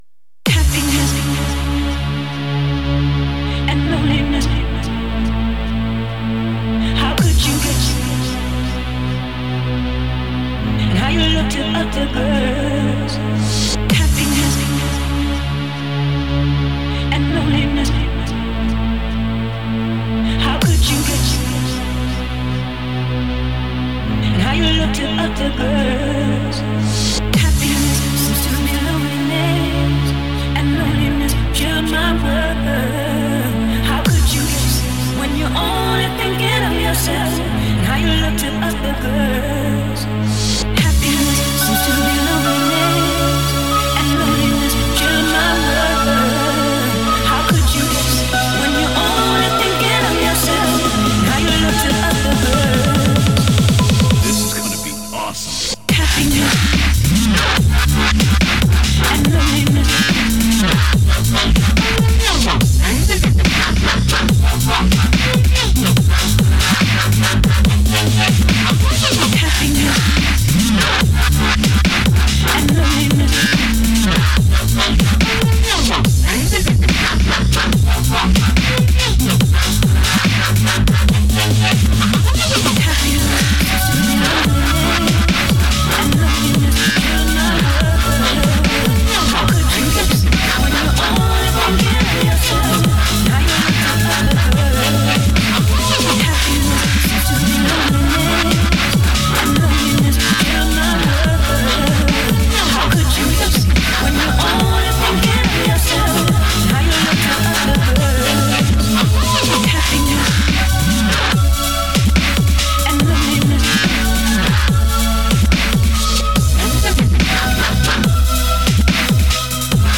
Clubby Breaks